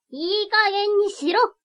「怒」のタグ一覧
ボイス
キュート女性